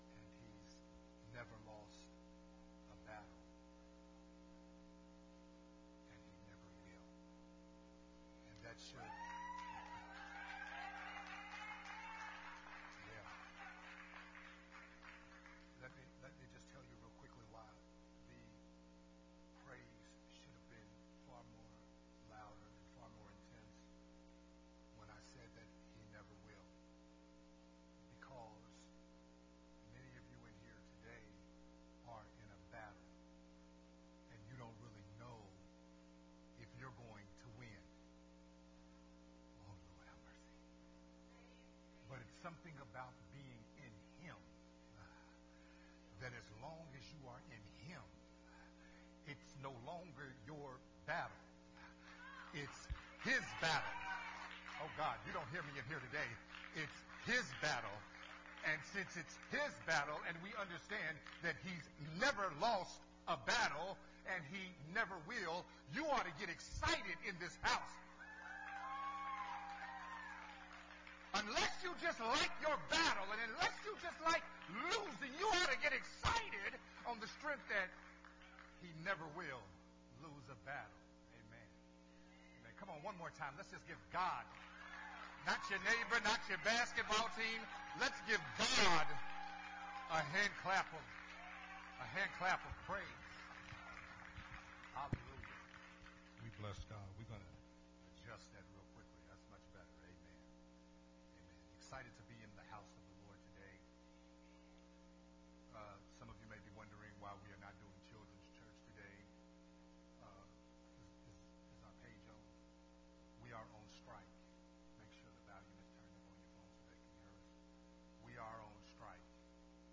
Part 3 of the sermon series “Starting Over”
recorded at Unity Worship Center on November 7, 2021